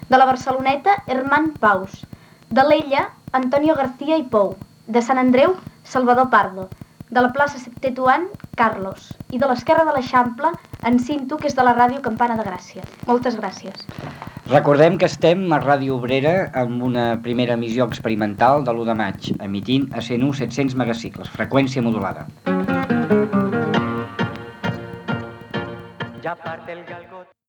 Emissió inaugural